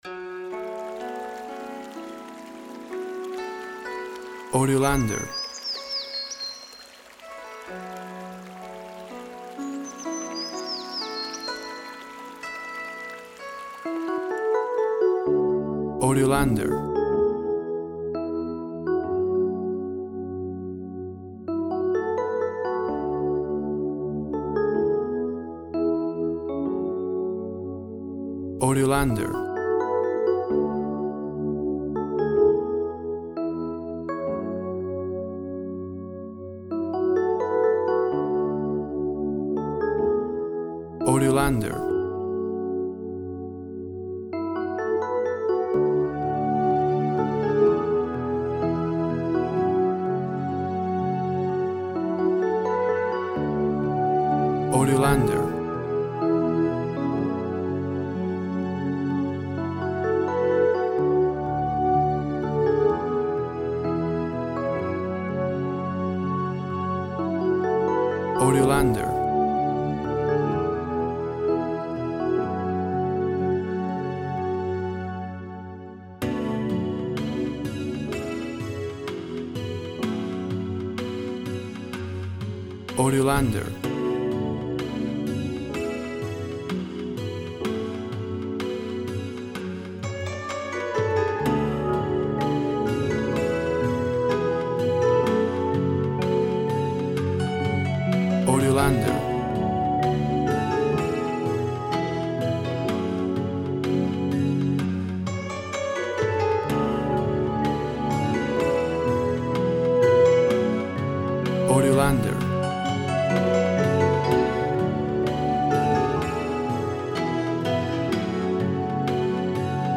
Traditional Asian Ambient Nature Adventure Music.
Tempo (BPM) 63